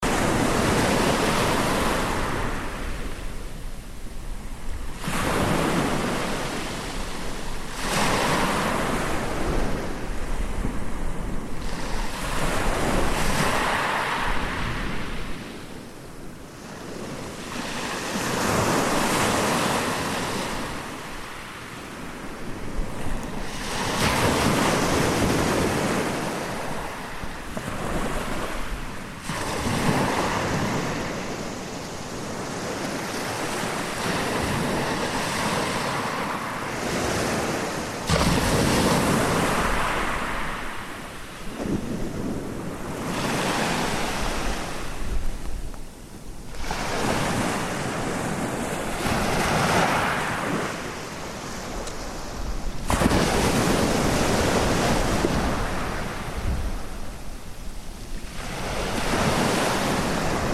Killiney Beach evening waves